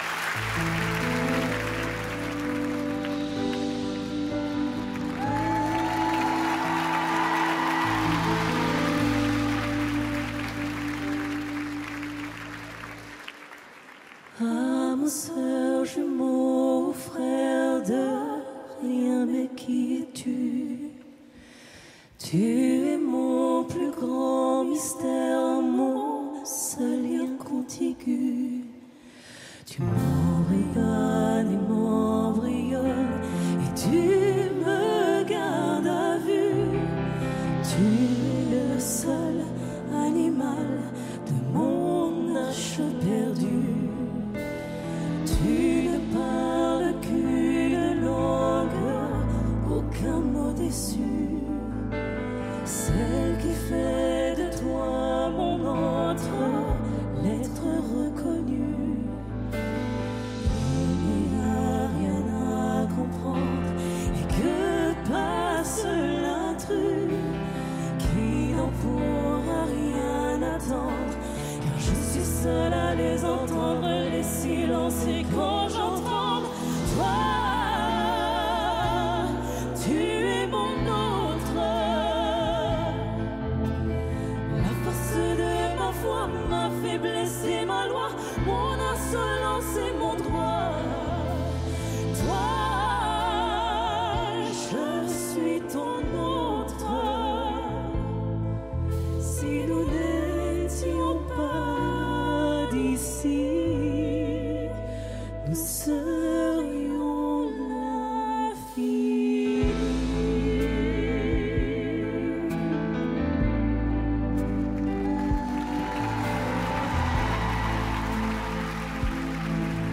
SPECTACLE